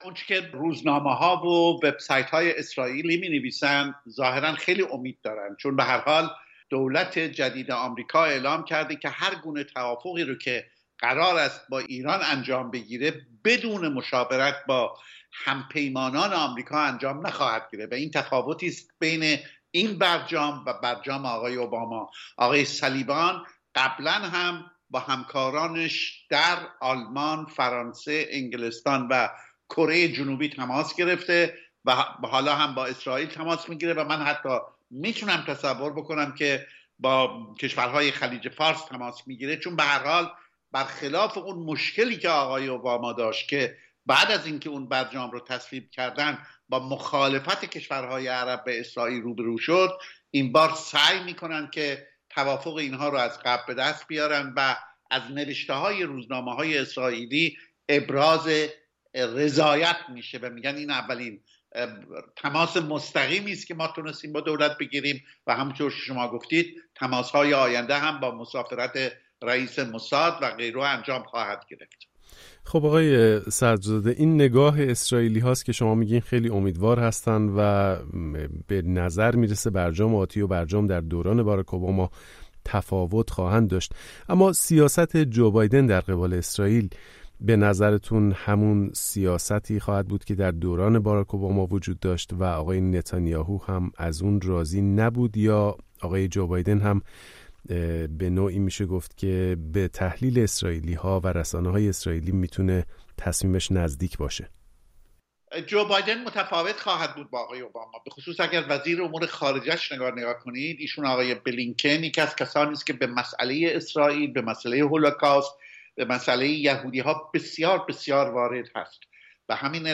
در گفت و گو با رادیو فردا